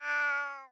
Minecraft Version Minecraft Version latest Latest Release | Latest Snapshot latest / assets / minecraft / sounds / mob / cat / ocelot / idle4.ogg Compare With Compare With Latest Release | Latest Snapshot